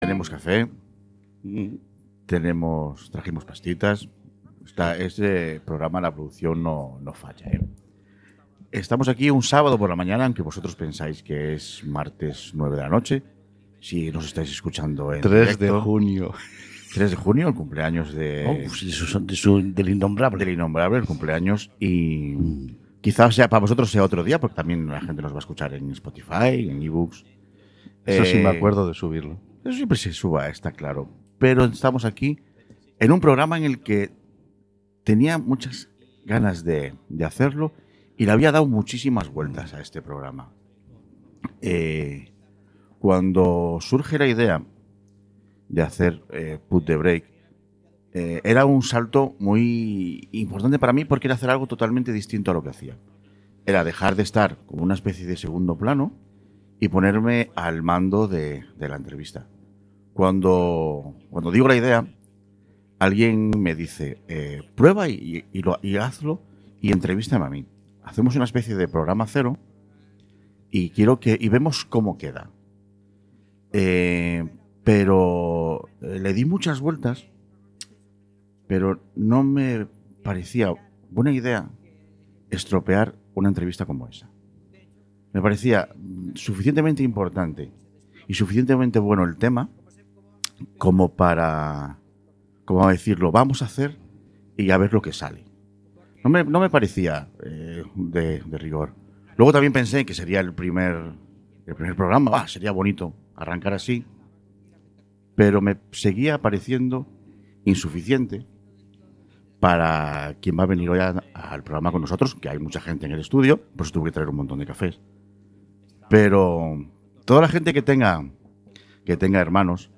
¡Prepárate para una conversación profunda sobre la pasión y los desafíos de los Deportes Minoritarios!